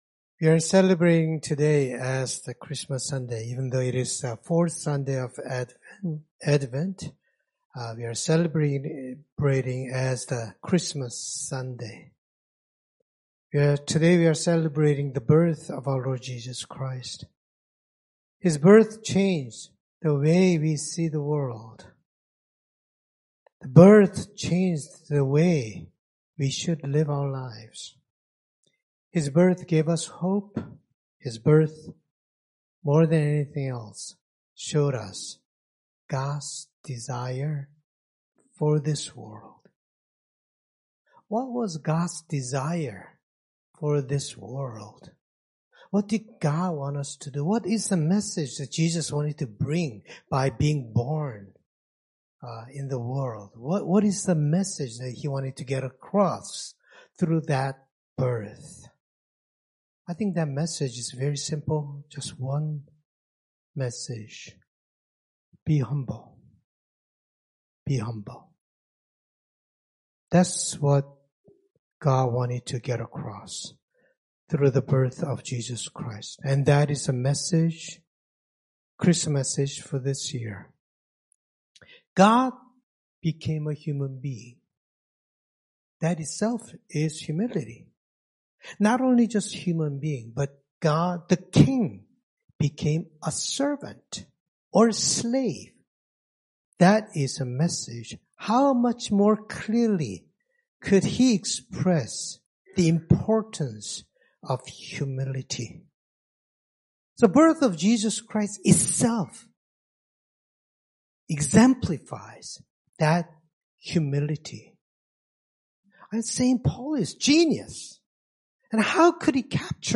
Scripture Passage Luke 1:46-55 Worship Video Worship Audio Sermon Script Merry Christmas!